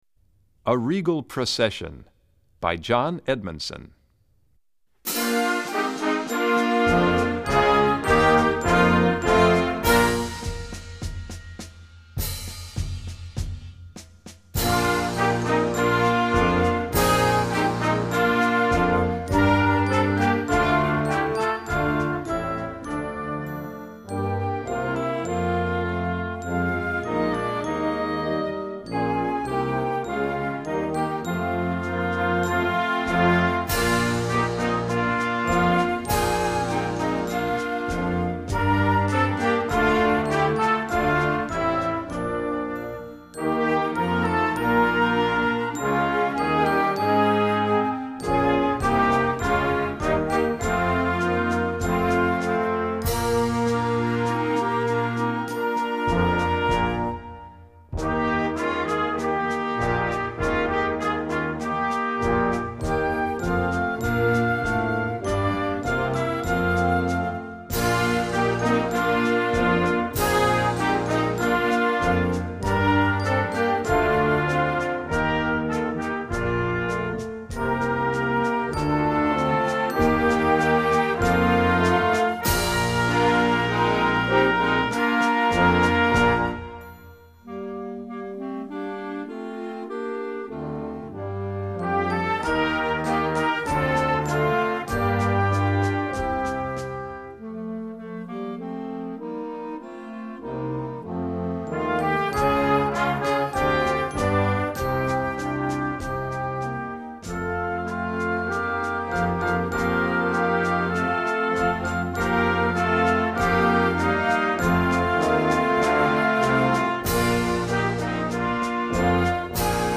Voicing: Full Score